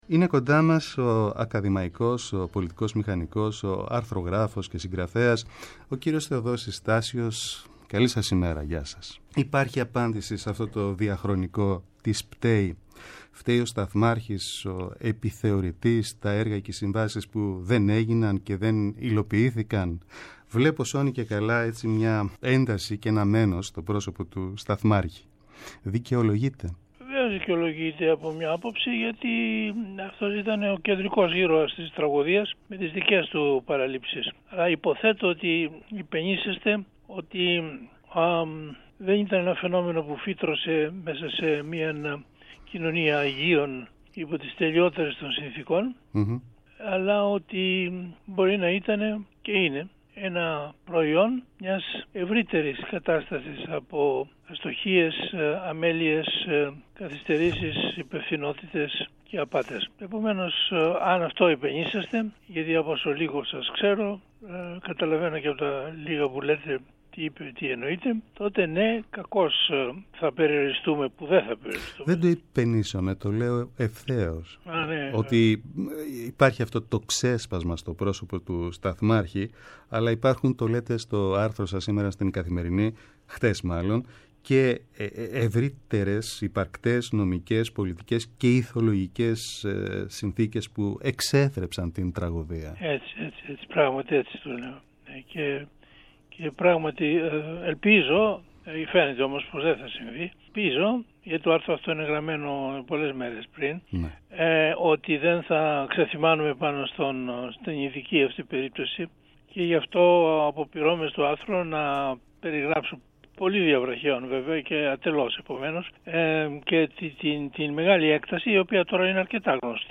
Ο Ακαδημαϊκός Θεοδόσης Τάσιος στο Πρώτο Πρόγραμμα | 06.03.23